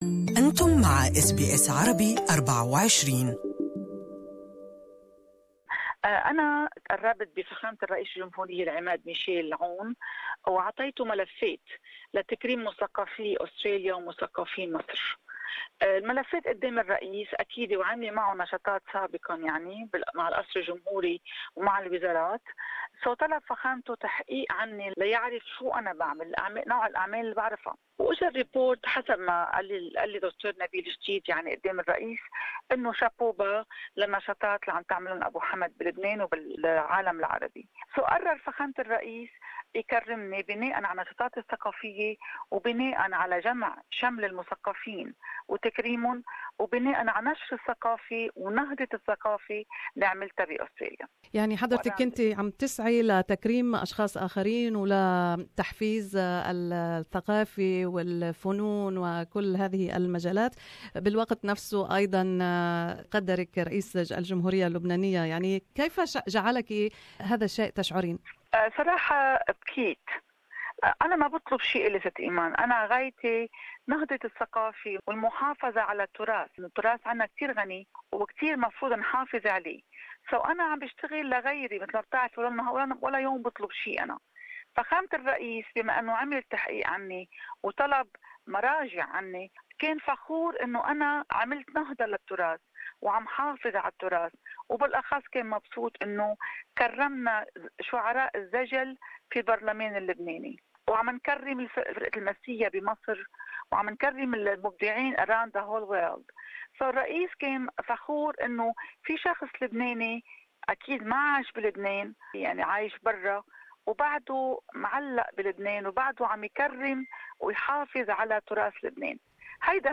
تتحدث في هذا اللقاء عن شعورها بهذا التكريم وعن نشاطاتها الأخيرة في كل من لبنان ومصر المتعلقة بالثقافة والشعر.